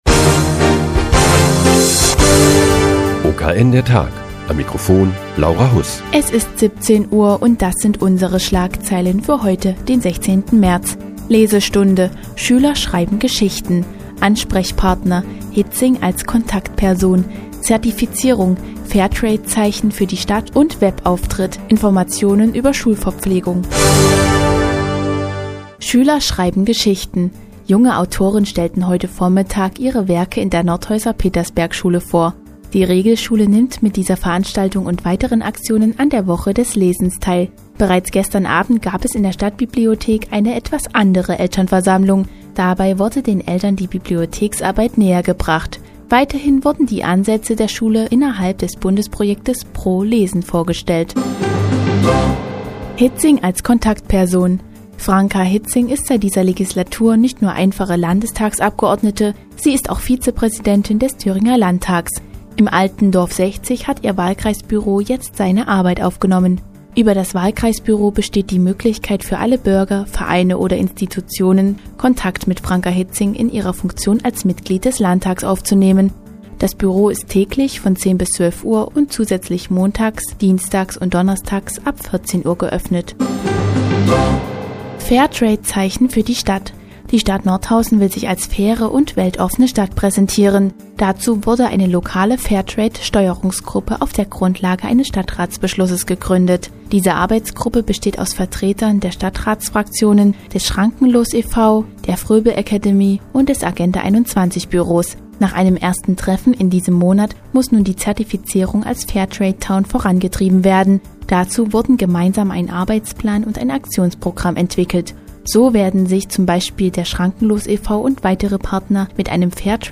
Die tägliche Nachrichtensendung des OKN ist nun auch in der nnz zu hören. Heute geht es um junge Autoren der Petersbergschule und Nordhausen als "Fairtrade Town".